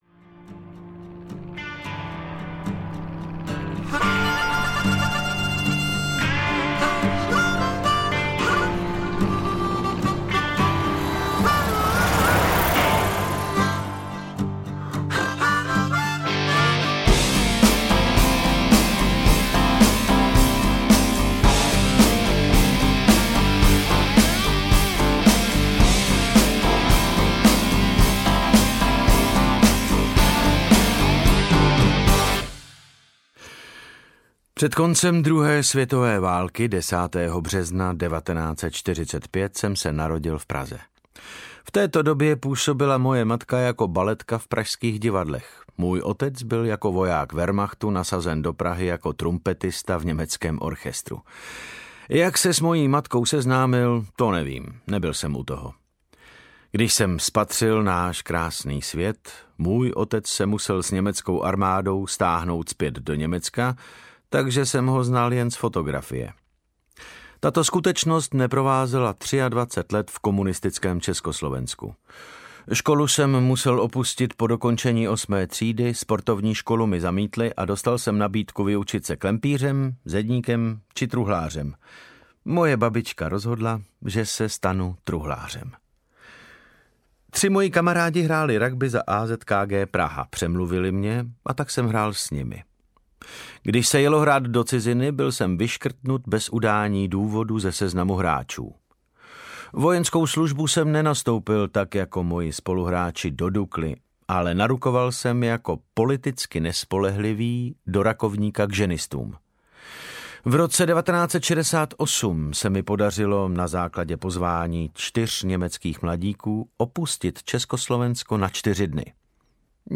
Audio knihaAlaska Joe
Ukázka z knihy
• InterpretDavid Novotný